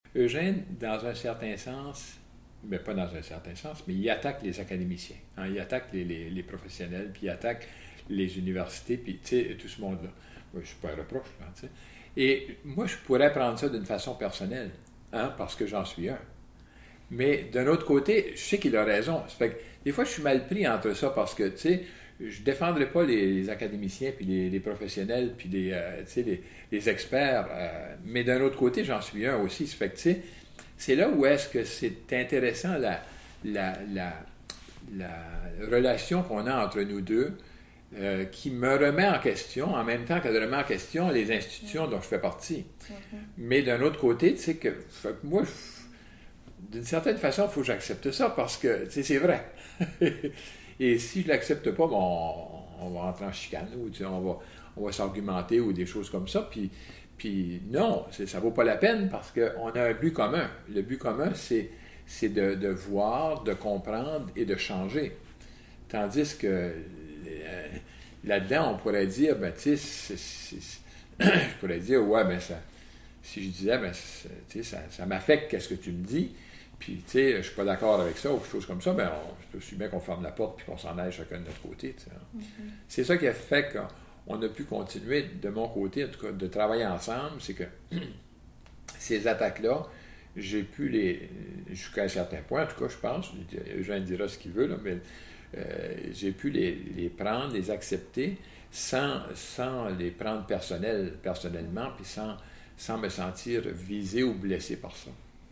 segments d’entrevues audio